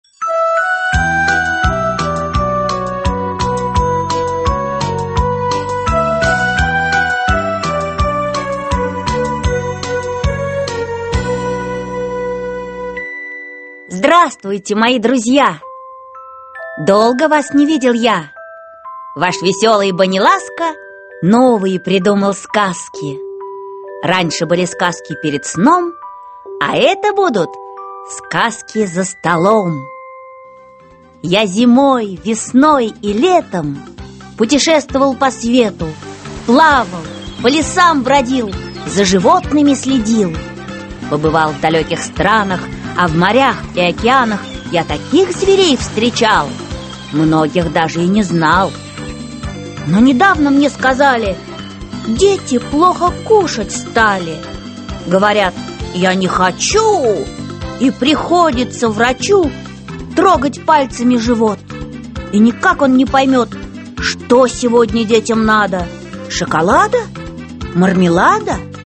Аудиокнига Кушай и слушай | Библиотека аудиокниг
Aудиокнига Кушай и слушай Автор М. Фримштейн Читает аудиокнигу Наталья Варлей.